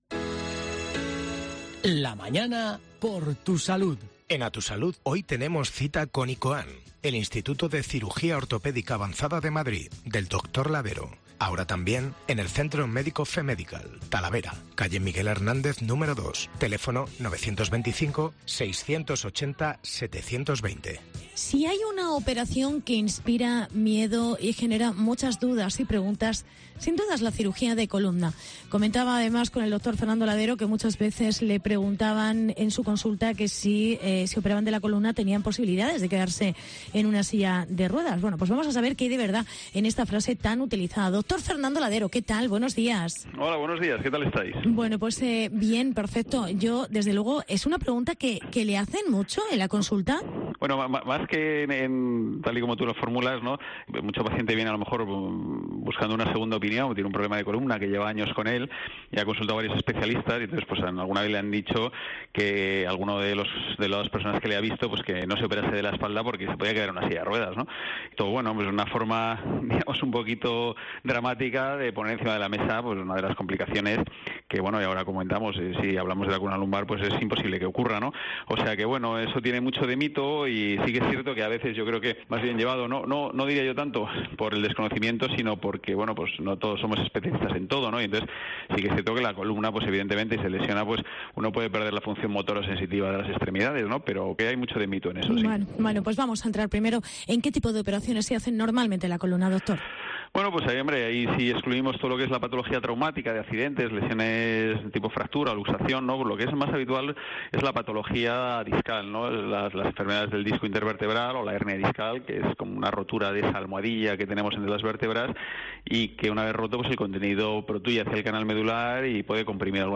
Operación de columna y sus mitos. Entrevista